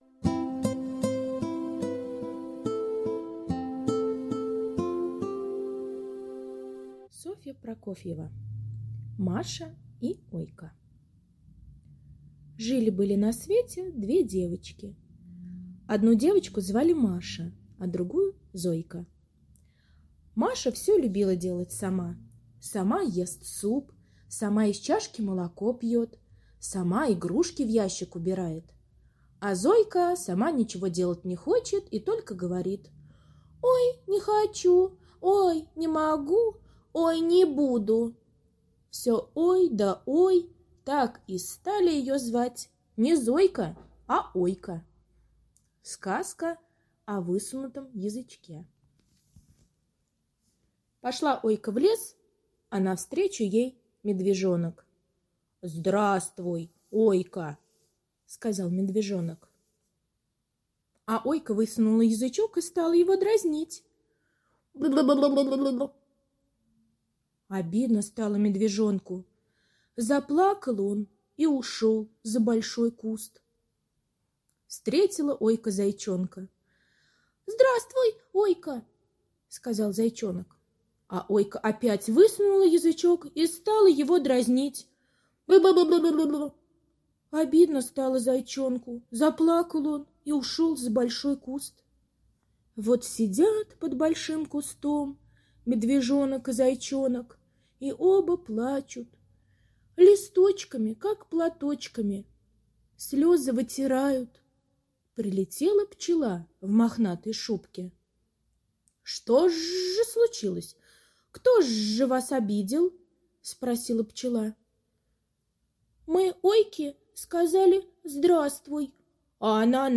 Сказка о высунутом язычке - аудиосказка Прокофьевой С. Сказка о том, как Ойка зверей в лесу дразнила, язык показывала.